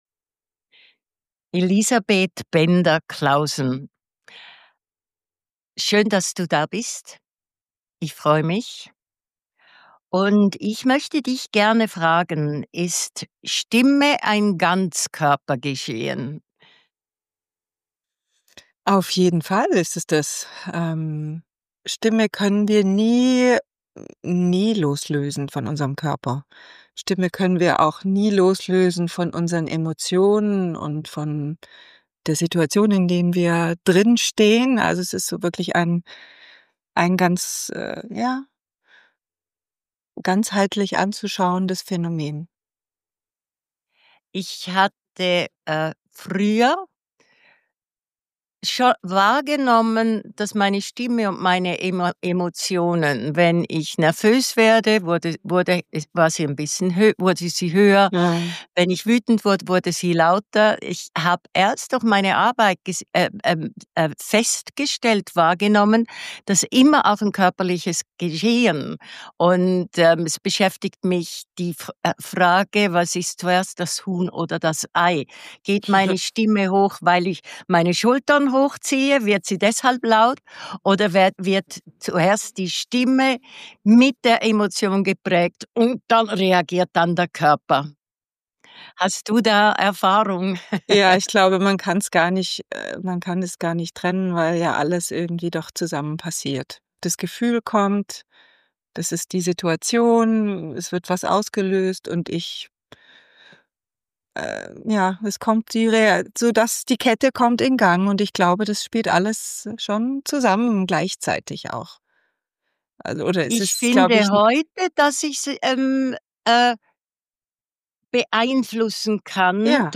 Über die Haltung verändert sich die Stimme sofort. Ein Gespräch über Stimme als Ganzkörpergeschehen, über Selbstwahrnehmung und darüber, wie sich Ausdruck verändert, wenn der Körper trägt.